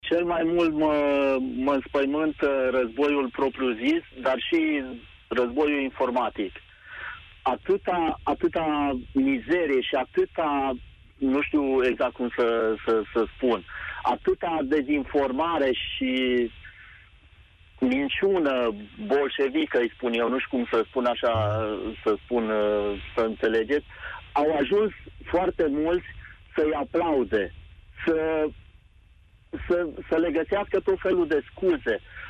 În emisiunea „România în Direct” am auzit și oameni care deși au anumite temeri, spun că teama și panica nu ne ajută, fiind două elemente dăunătoare din viața noastră.
14mar-RID-ascultator3-ma-inspaimanta-razboiul-propriu-zis-si-cel-informatic.mp3